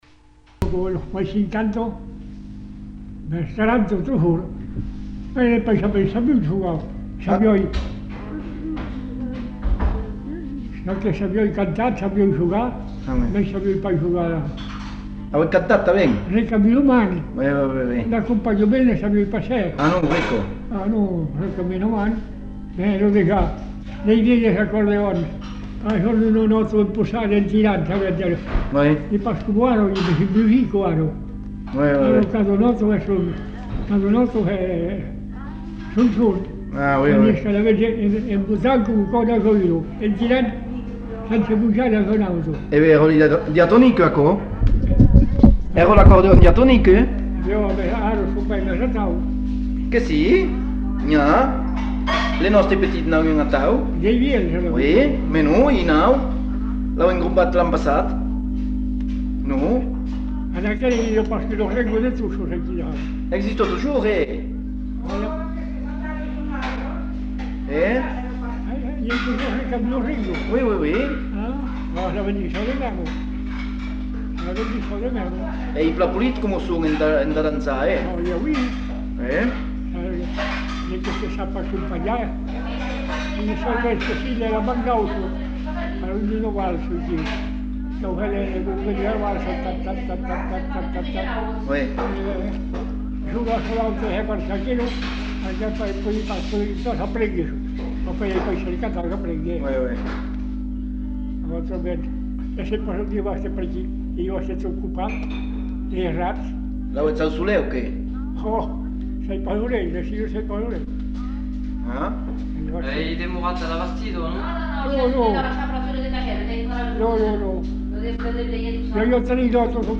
Lieu : Lherm
Genre : témoignage thématique